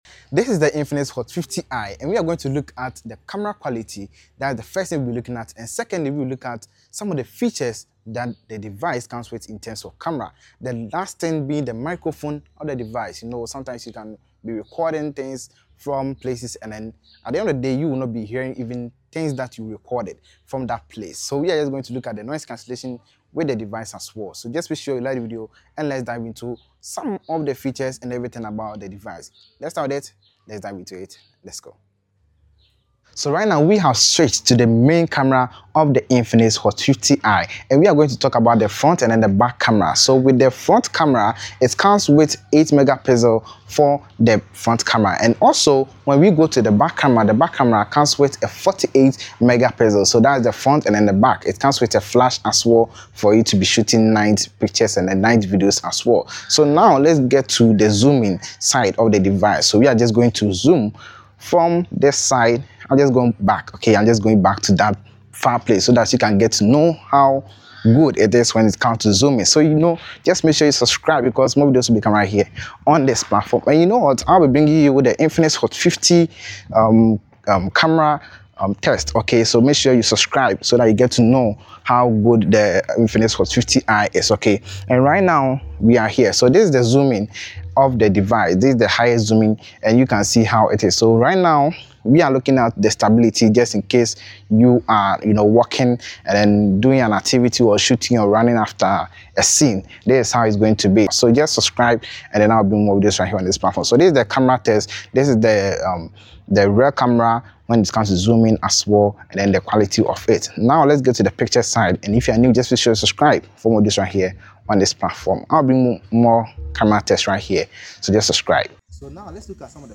Infinix Hot 50i Camera Test - Quality, Features and Microphone Test